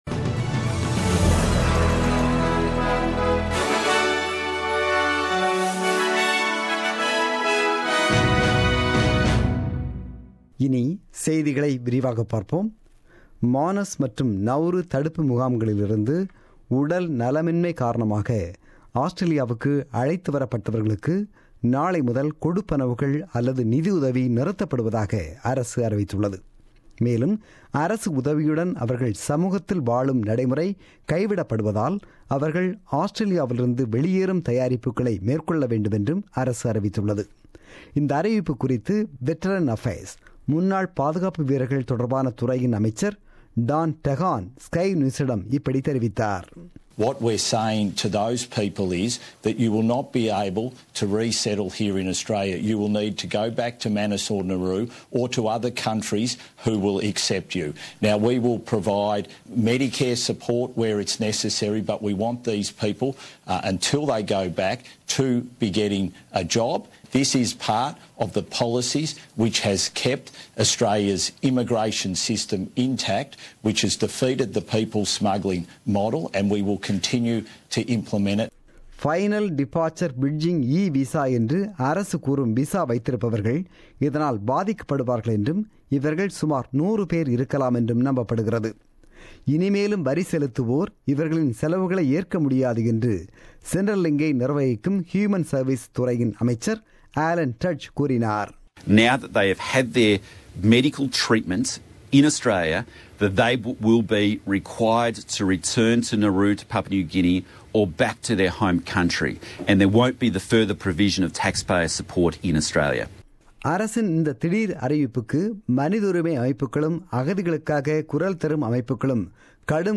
The news bulletin broadcasted on 27 August 2017 at 8pm.